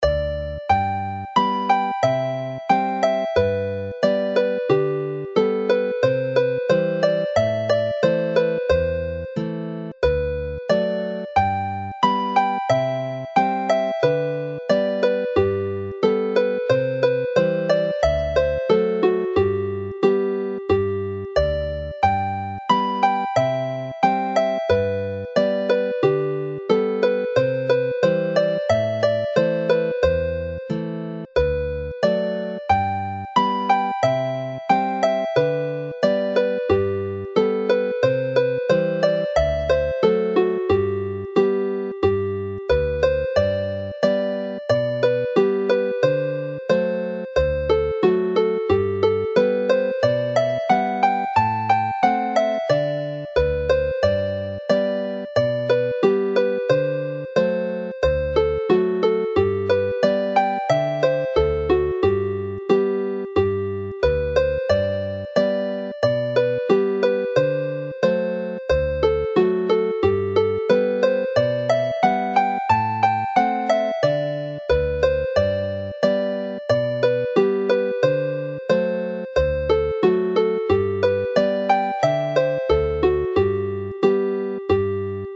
Play the tune slowly